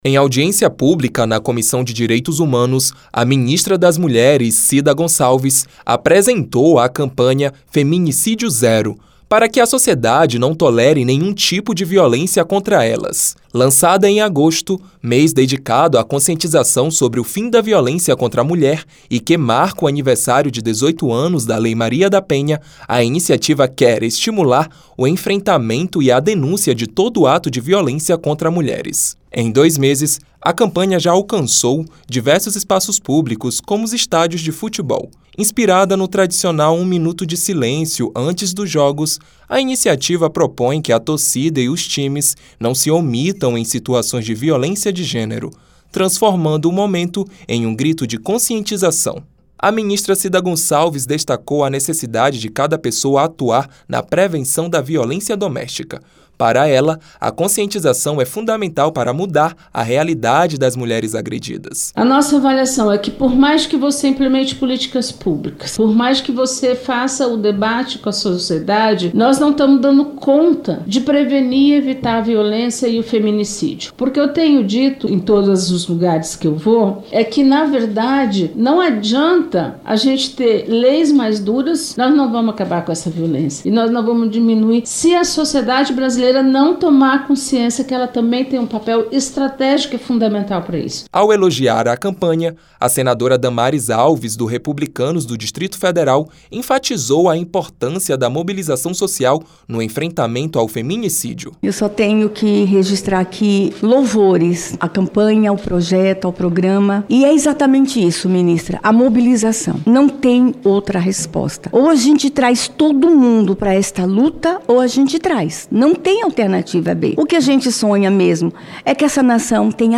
Em audiência pública na Comissão de Direitos Humanos (CDH) nesta quinta-feira (31), a ministra das Mulheres, Cida Gonçalves, apresentou a campanha “Feminicídio Zero”. A iniciativa tem o objetivo de engajar a sociedade no combate à violência contra as mulheres, incentivando as denúncias contra esse tipo de crime.